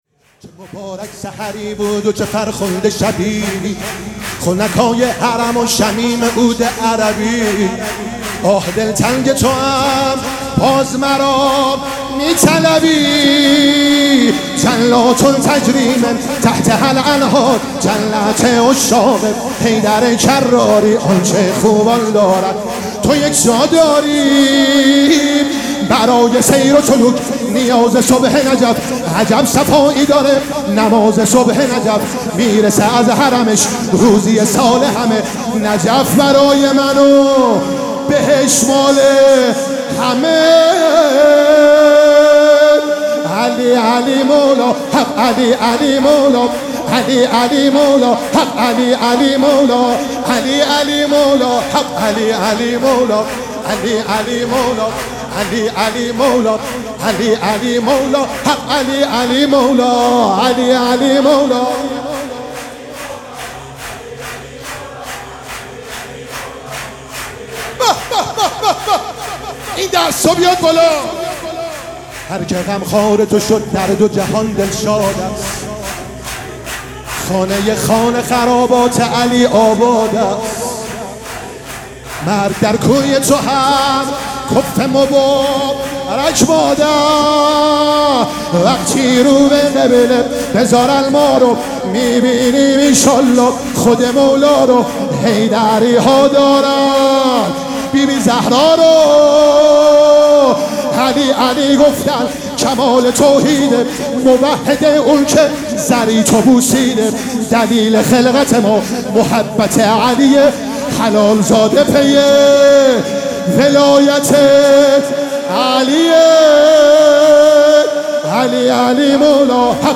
مراسم جشن ولادت امیرالمومنین(ع)
شور